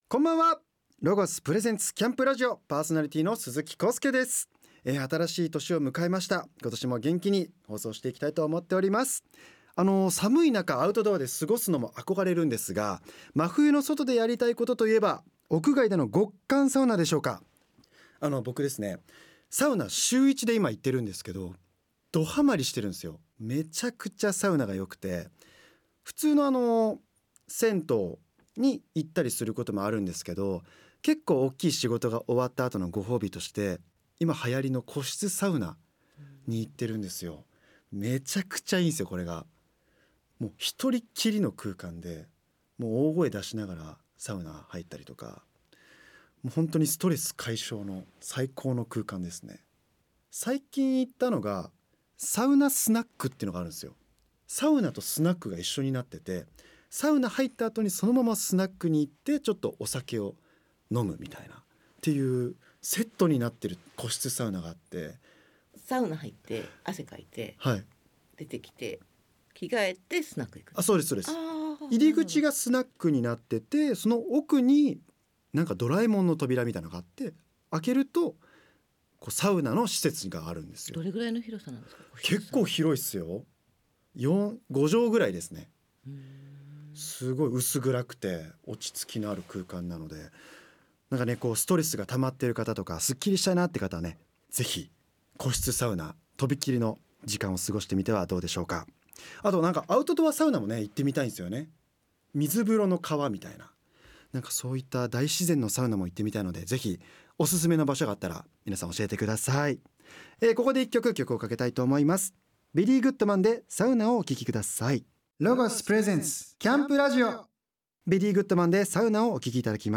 毎回、様々なジャンルの方々をゲストに迎え、アウトドアをはじめ、ゲストの専門分野や、得意なコト、夢中になっているコトなどをじっくり伺い、その魅力に迫る番組です。アウトドアに役立つ情報も満載のCAMPRADIO、ぜひお楽しみください。